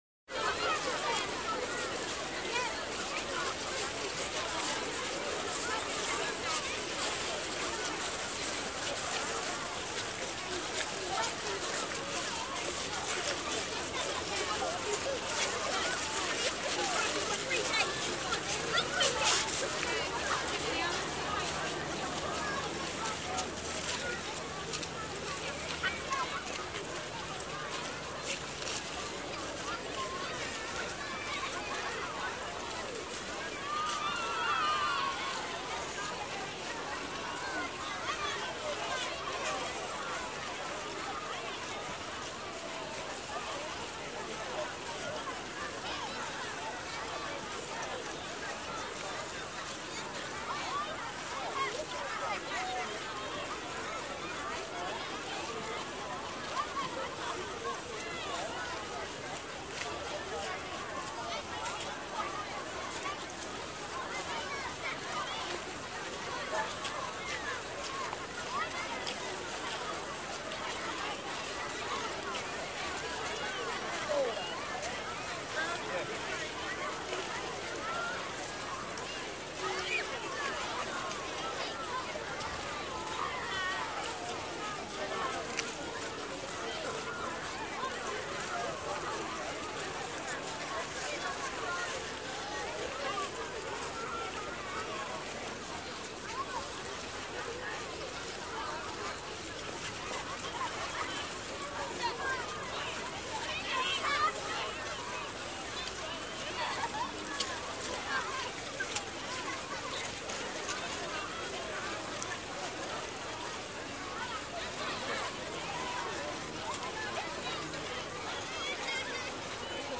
Разговоры и шум толпы детей на улице